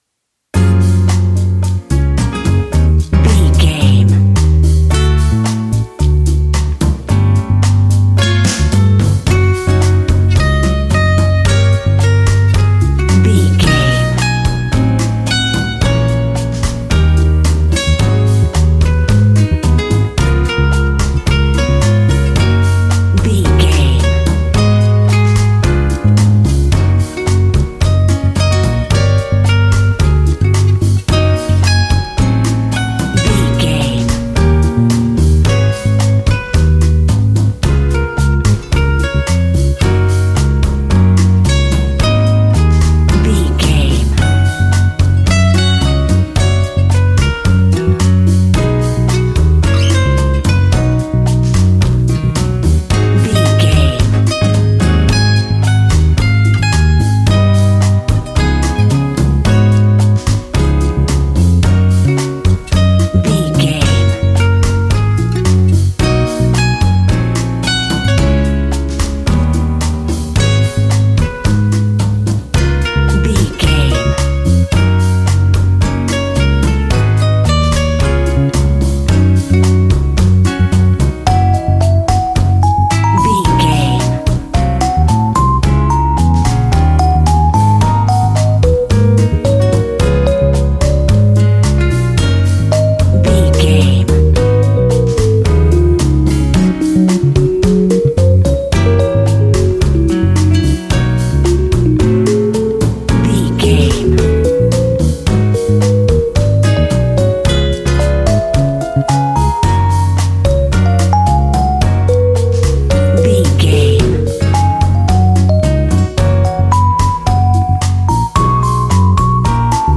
An exotic and colorful piece of Espanic and Latin music.
Ionian/Major
F#
maracas
percussion spanish guitar